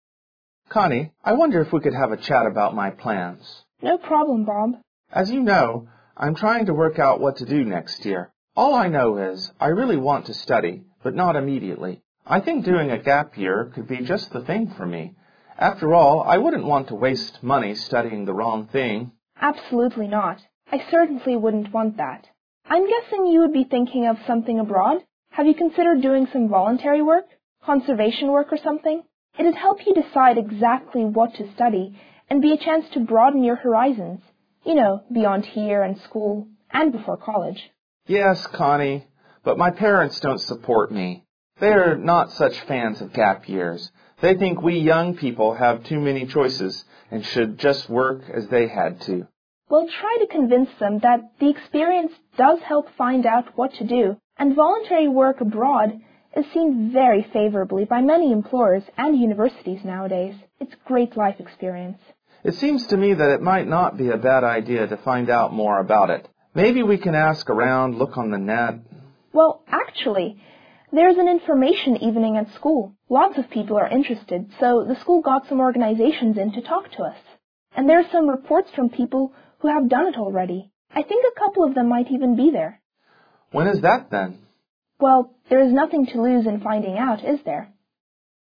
For items 11–15 listen to the dialogue.